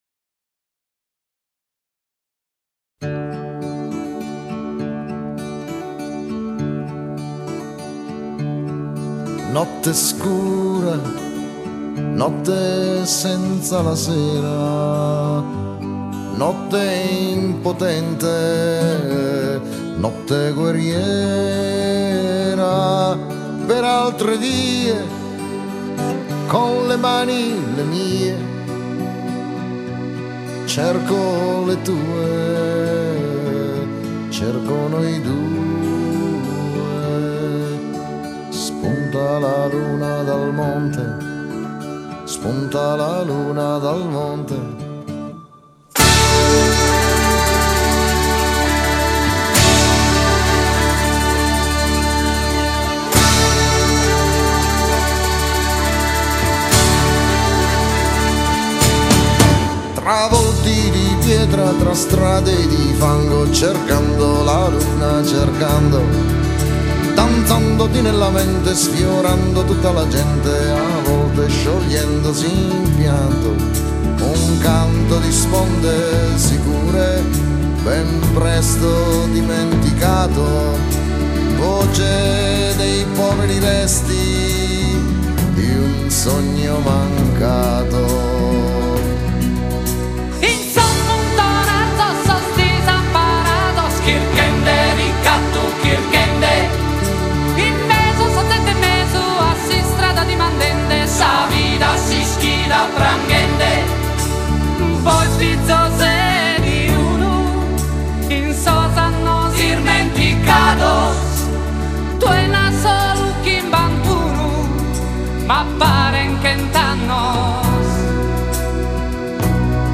Versione cantata
a Sanremo